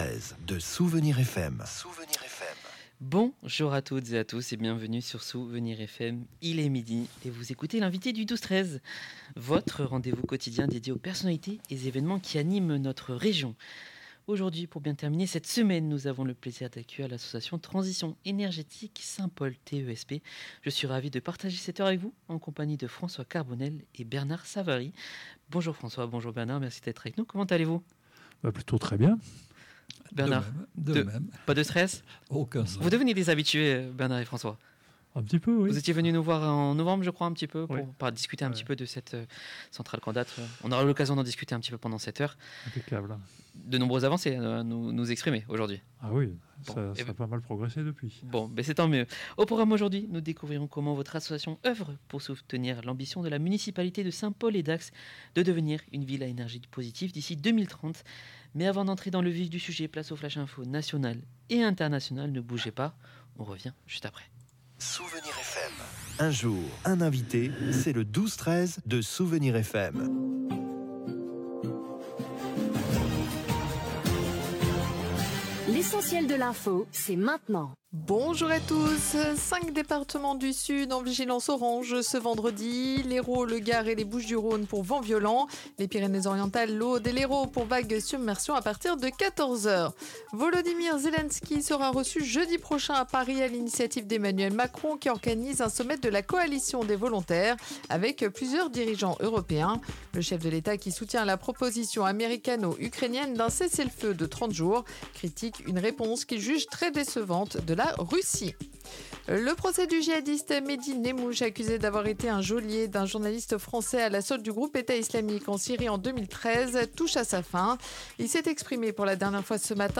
représentants de l’association Transition Énergétique Saint-Paul (TESP).